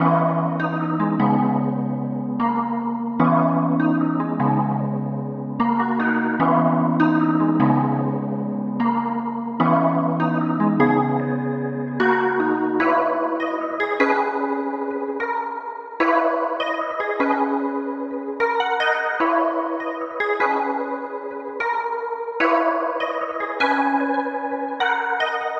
Saint - 150 bpm.wav